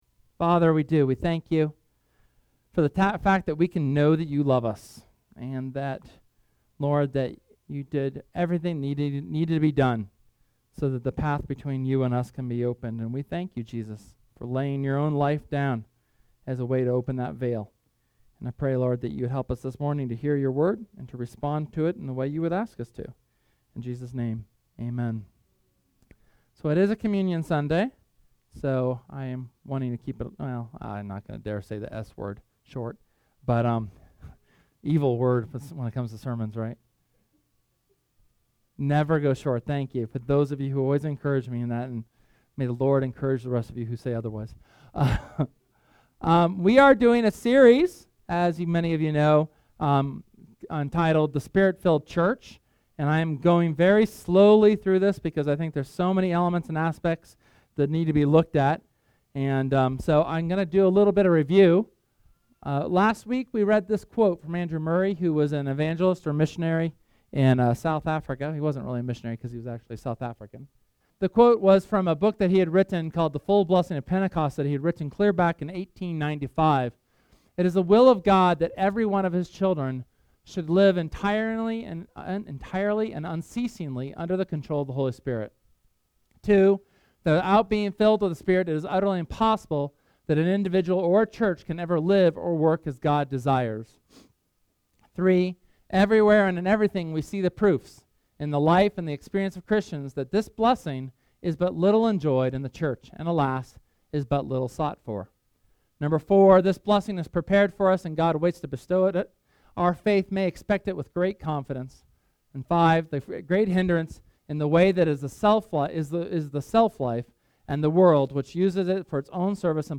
SERMON: The Holy Spirit is…